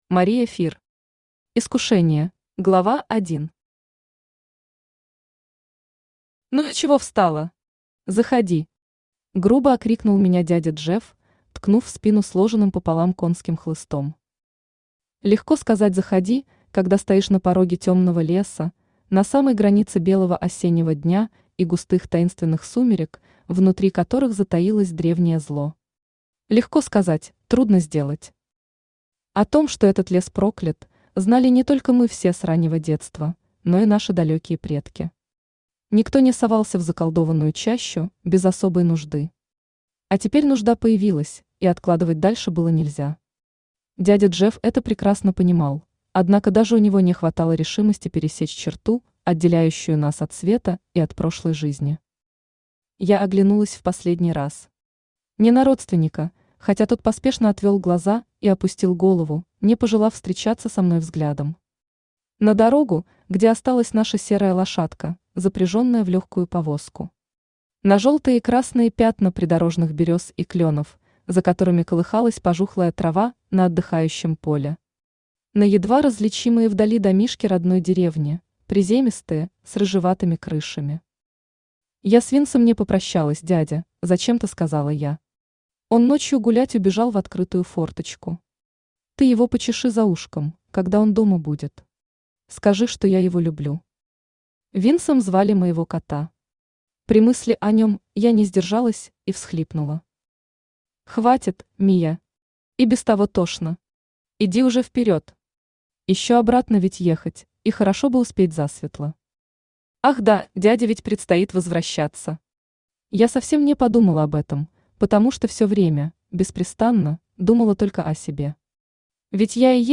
Аудиокнига Искушение | Библиотека аудиокниг
Aудиокнига Искушение Автор Мария Фир Читает аудиокнигу Авточтец ЛитРес.